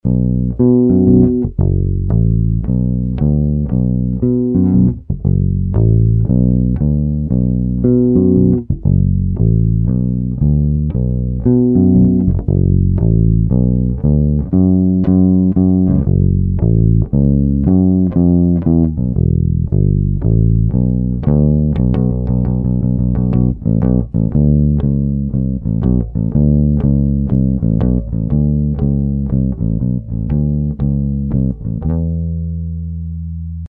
The Ampeg™ SB-12 Portaflex™ adapted for use as a distortion stompbox.
We chose the SB-12 model Portaflex as our starting point for a FET-based bass overdrive.
Customer Clips: